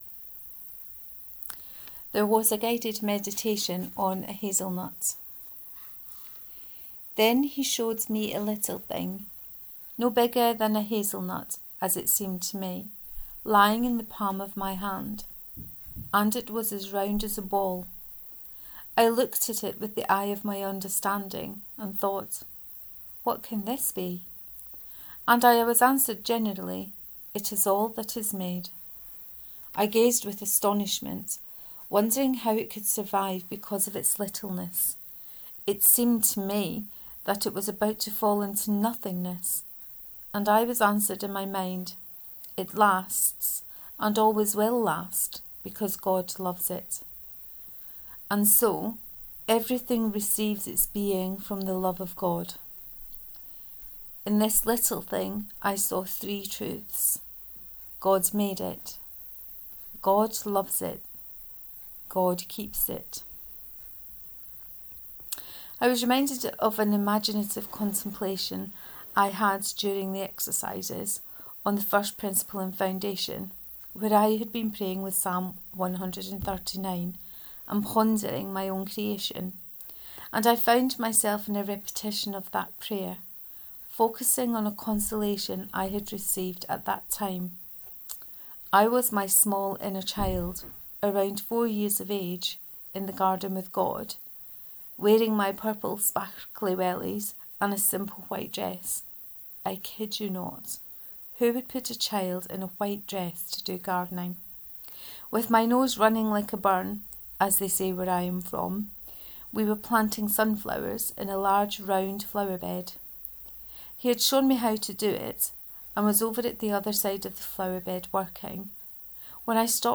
Reading of All things in a hazelnut part 3